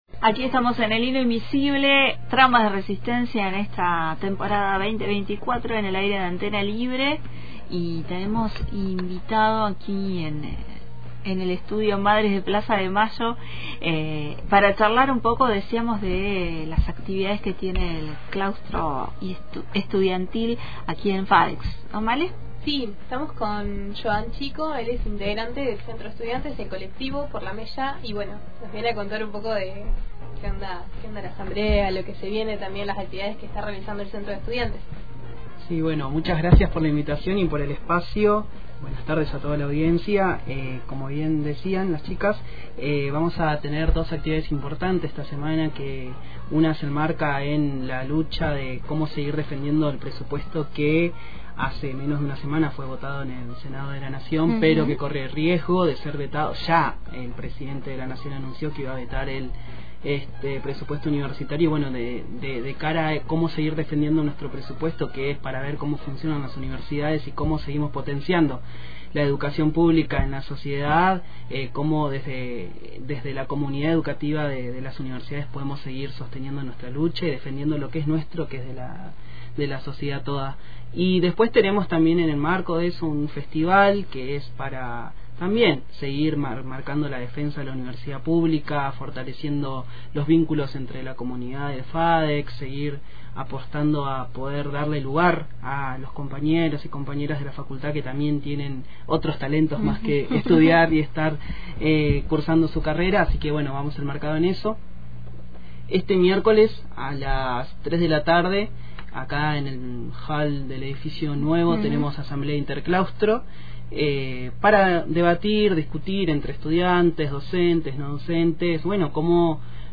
Escucha la entrevista completa acá: